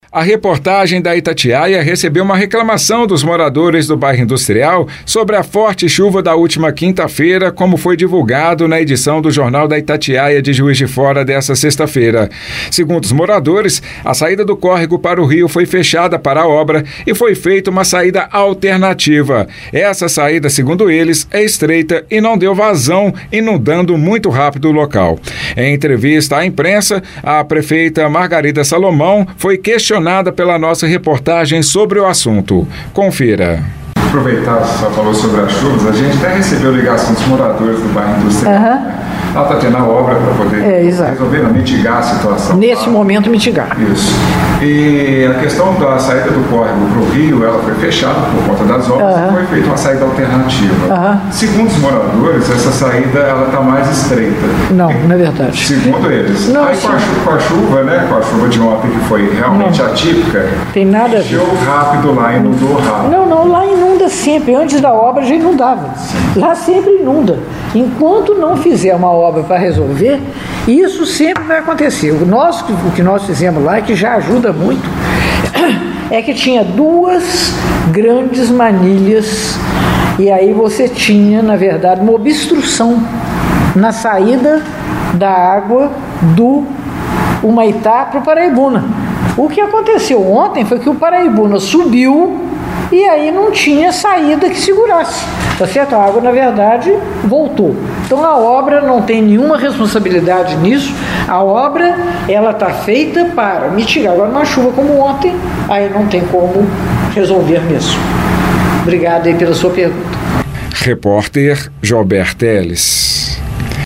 As contestações sobre o impacto da obra em andamento para o transtorno diante do grande volume de chuva foram levadas à Prefeita Margarida Salomão durante coletiva às emissoras de rádio nesta sexta (27).